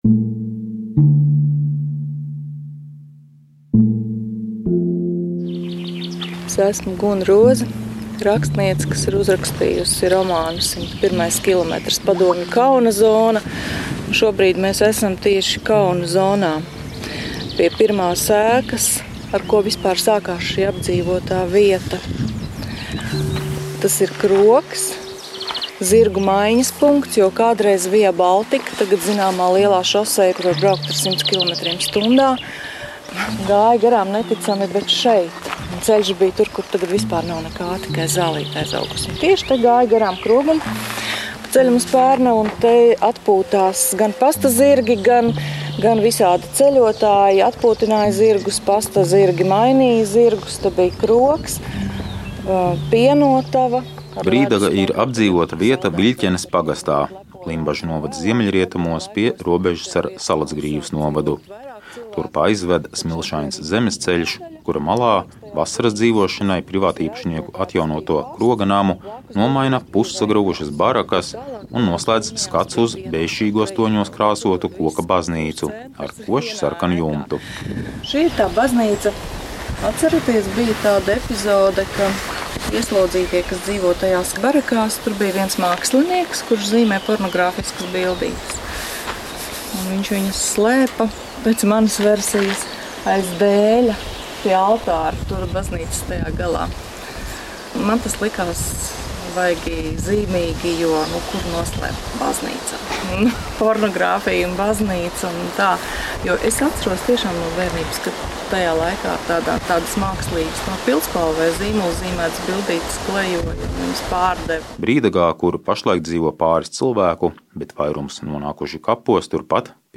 Stāstu sērijā par iekšējām deportācijām dodamies uz Brīdagu, lai romāna vietas izstaigātu kopā ar rakstnieci.